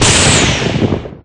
Thunder6.ogg